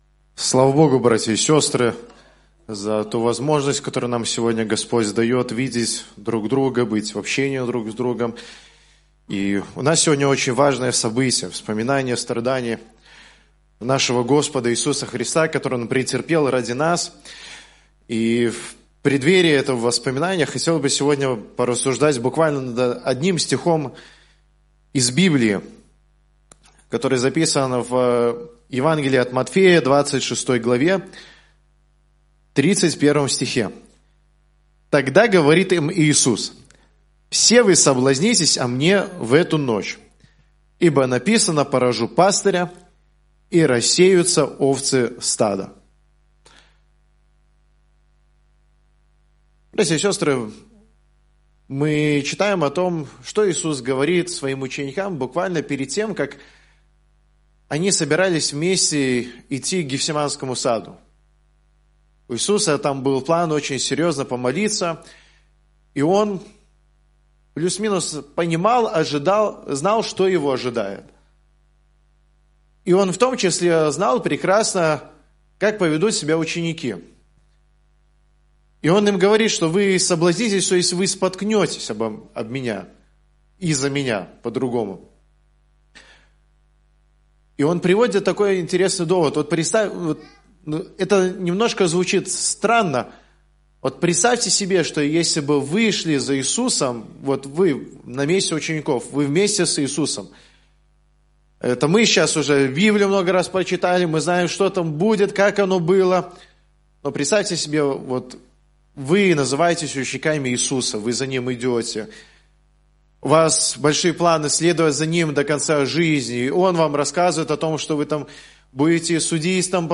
Гефсимания - Проповеди